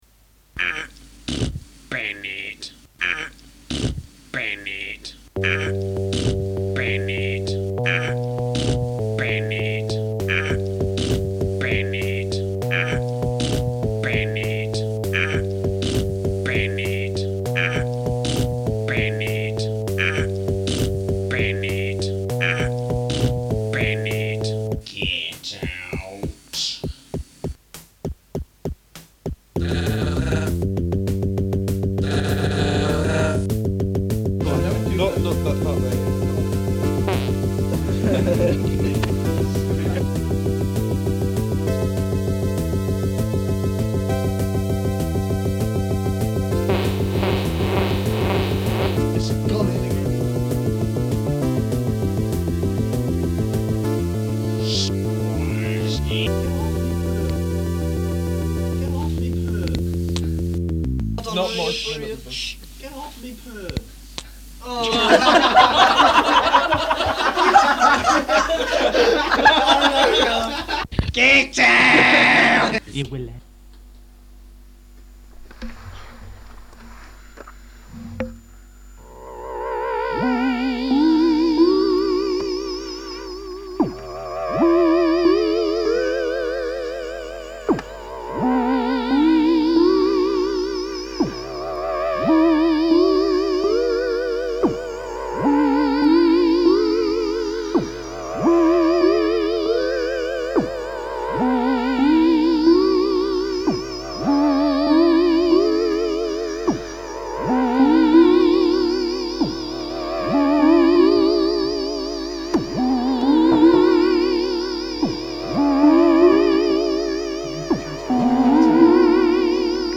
Mostly MIDI.
Track 2 is a fun piece of synthscaping.